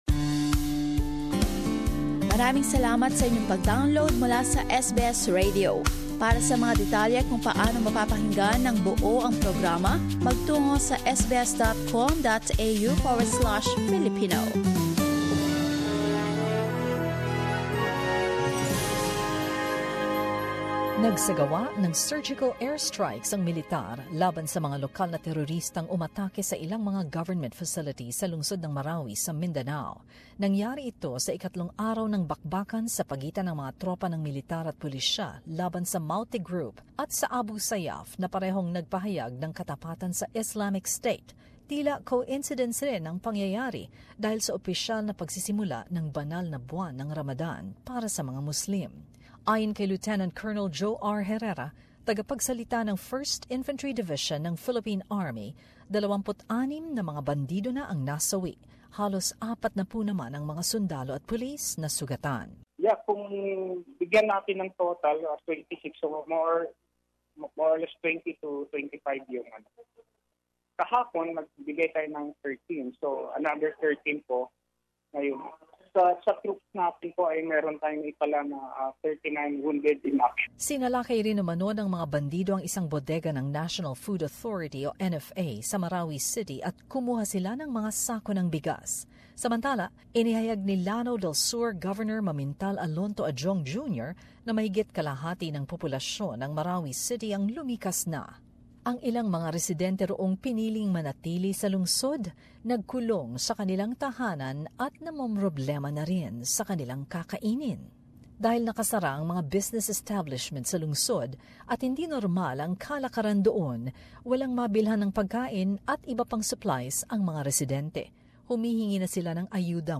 Report on the current situation in Marawi City and the reactions to the recent declaration of Martial Law in Mindanao Image: Philippine President Rodrigo Duterte, center, signs the report he submitted to congress for declaring martial law and suspending the writ of habeas corpus in Mindanao beside…